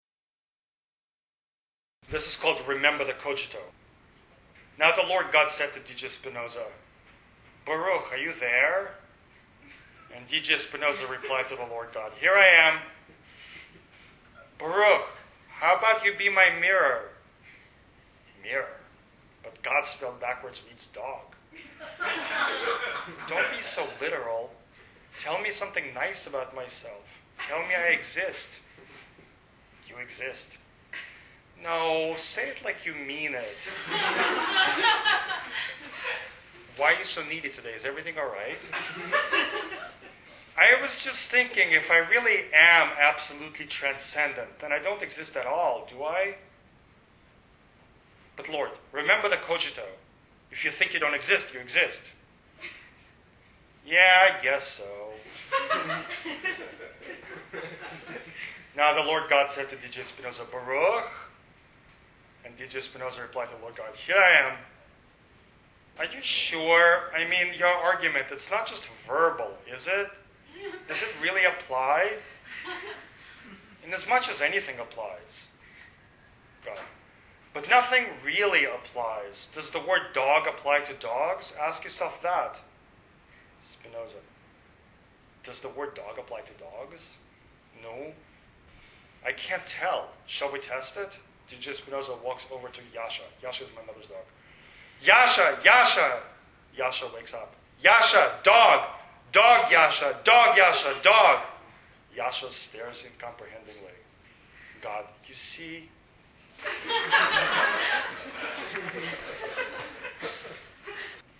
recorded live at Bowdoin College, Brunswick, Maine,
Remember_the_Cogito_live.mp3